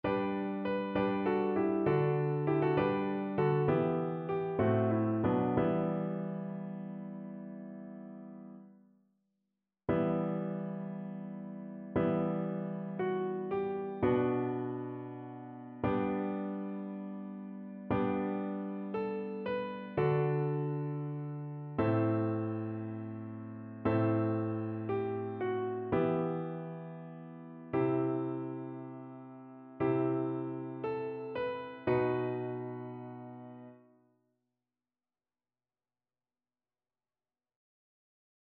ChœurSopranoAltoTénor
annee-c-temps-ordinaire-sainte-trinite-psaume-8-satb.mp3